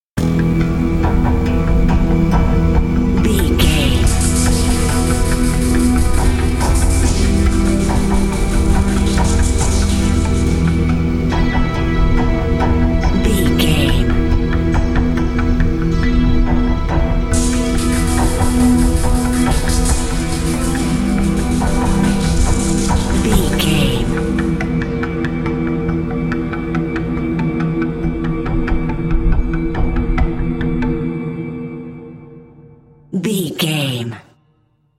Thriller
Aeolian/Minor
synthesiser